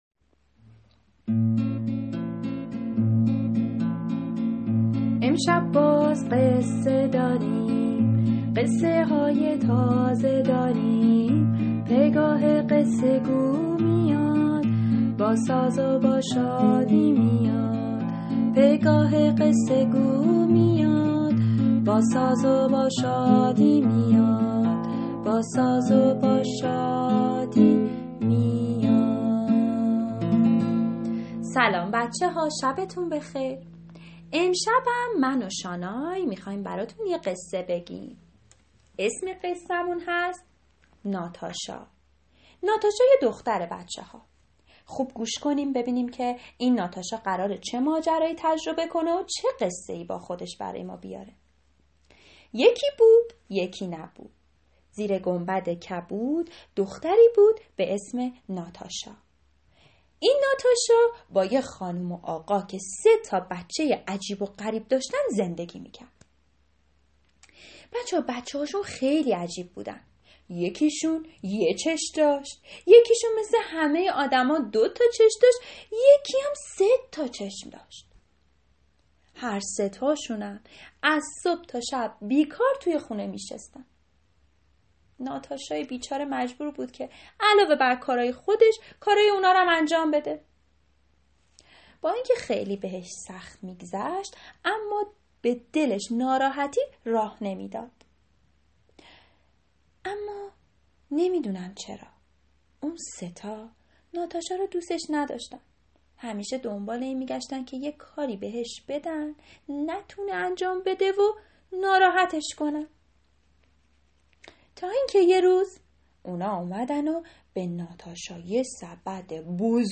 قصه کودکانه صوتی ناتاشا